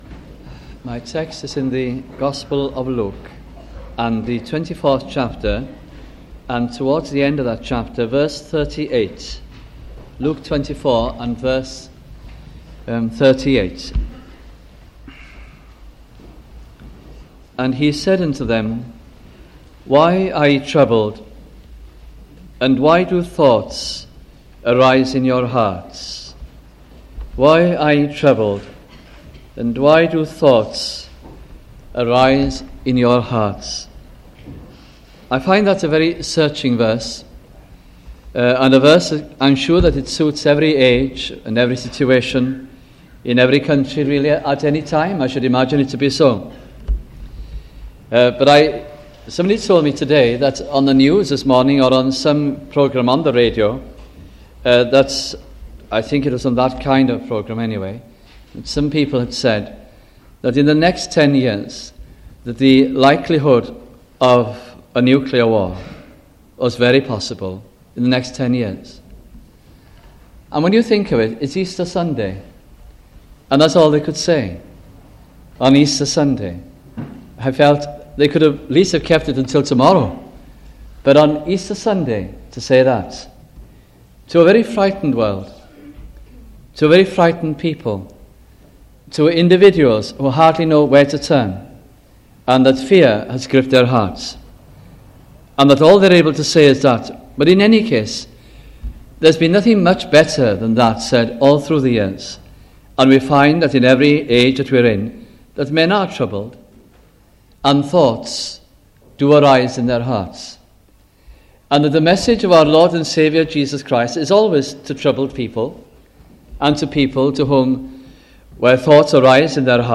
» Luke Gospel Sermons